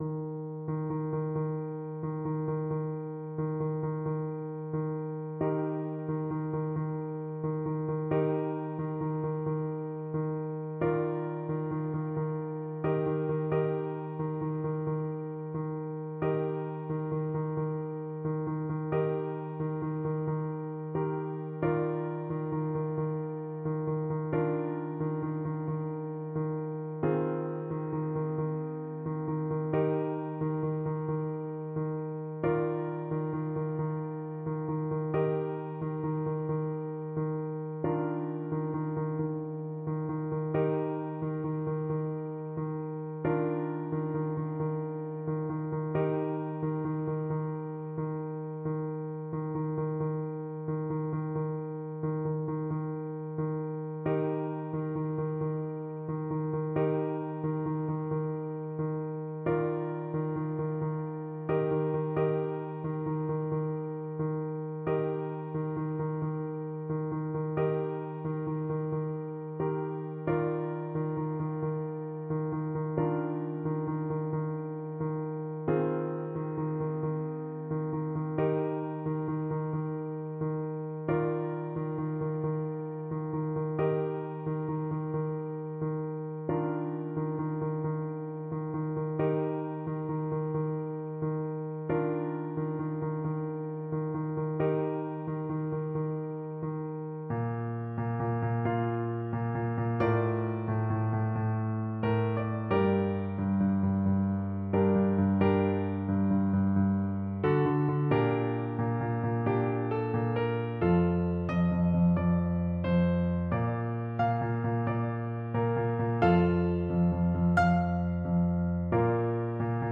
Trumpet
4/4 (View more 4/4 Music)
Nobilmente = c. 60
Arrangement for Trumpet and Piano
Eb major (Sounding Pitch) F major (Trumpet in Bb) (View more Eb major Music for Trumpet )
Traditional (View more Traditional Trumpet Music)